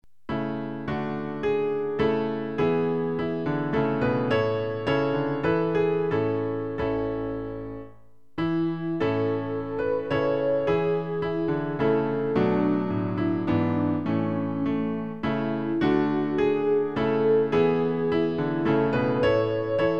Klavier-Playback zur Begleitung der Gemeinde
MP3 Download (ohne Gesang)